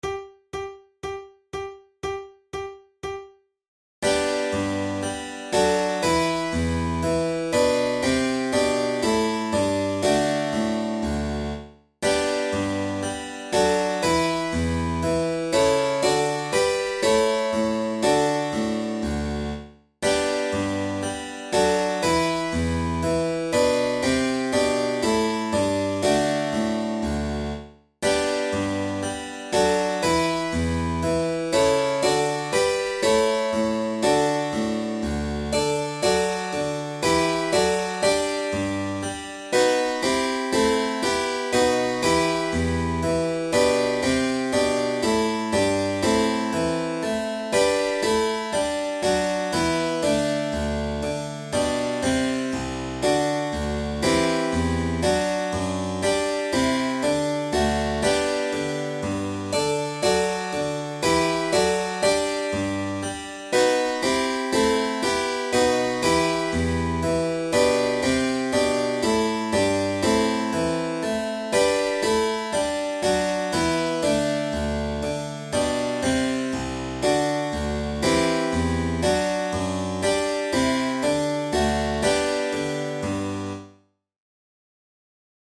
Arranged for flute and keyboard
Categories: Baroque Gavottes Difficulty: easy